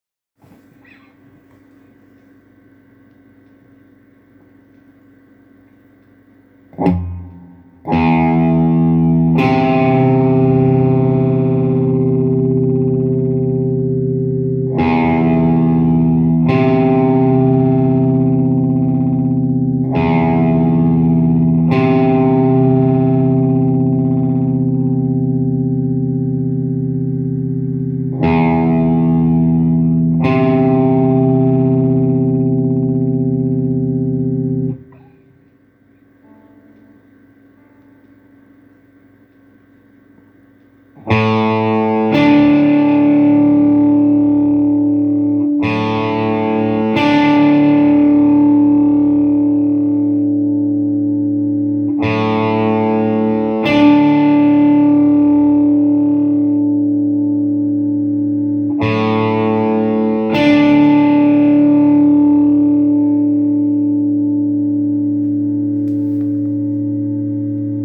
Ursache bzw. Abhilfe für Nebengeräusche ("flatternder" Sound)?
Es entsteht zum Einen ein Flattern, zum anderen klingt es so, als ob sich die einzelnen Töne der Saiten überschlagen und vermischen würden. Es klingt wie ein großer Knäuel aus Tönen.
Zu meinen Sounddateien: Bei beiden Aufnahmen benutze ich nur den normalen Overdrive-Channel meines Verstärkers (Keine Effektgeräte) Die Aufnahmen habe ich mit meinem Handy gemacht, eventuell ist die Qualität so schlecht, dass man nichts raushört.
Meine Ausrüstung: Gitarre: Ibanez ART 320 (2x Aktive Hambucker) Verstärker: Marshall MG 50DFX Marshall MG 50FX Ich hoffe, dass mir (und vielleicht auch anderen) hier weitergeholfen werden kann.